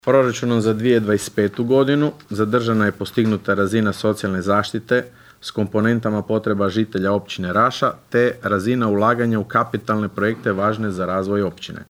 Na sinoćnjoj sjednici Općinskog vijeća Raše, općinski načelnik Leo Knapić podnio je izvješće o radu za prvih šest mjeseci 2025. godine.
ton – Leo Knapić).